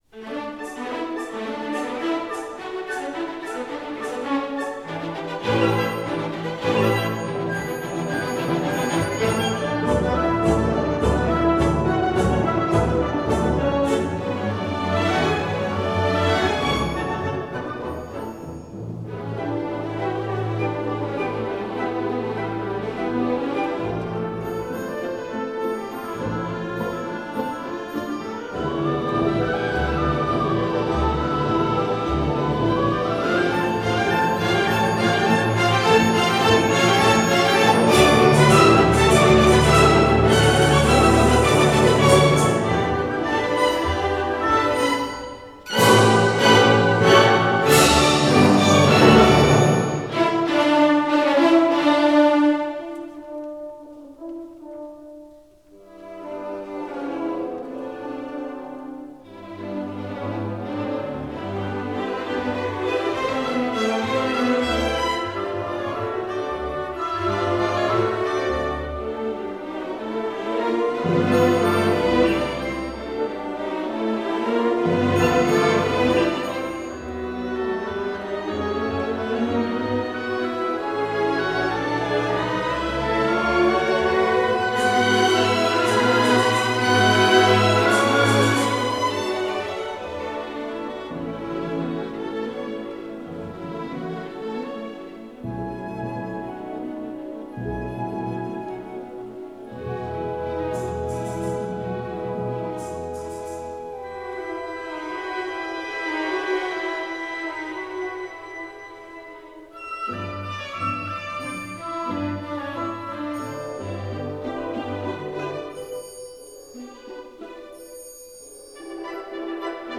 Orquesta